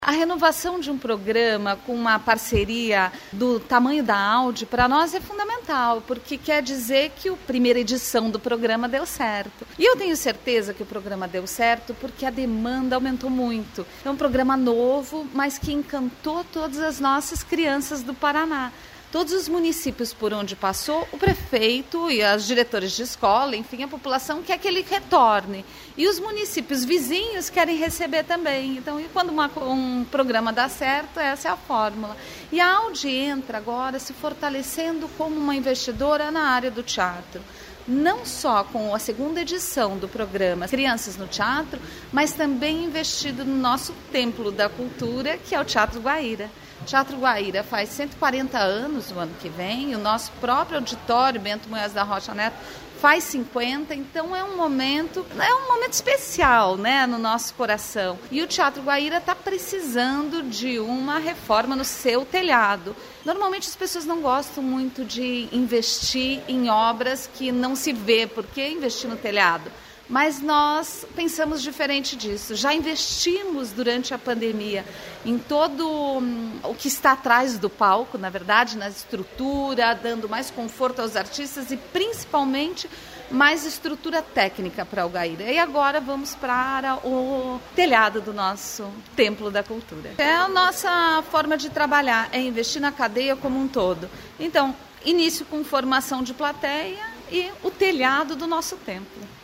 Sonora da secretária da Cultura, Luciana Casagrande, sobre a parceria com a Audi para levar teatro a crianças e reformar o Guaíra